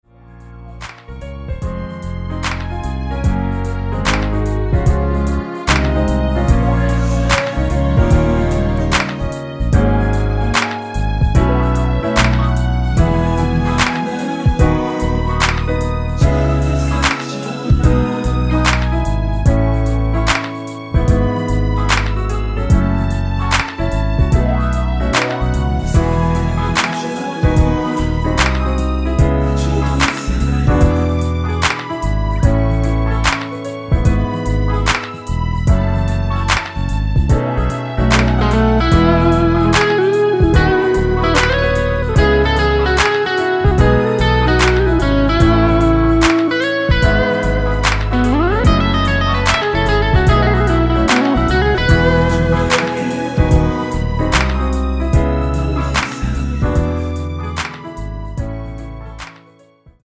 (-2)내린 코러스 포함된 MR 입니다.(미리듣기 참조)
Db
앞부분30초, 뒷부분30초씩 편집해서 올려 드리고 있습니다.
중간에 음이 끈어지고 다시 나오는 이유는